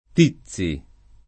tizi [ t &ZZ i ]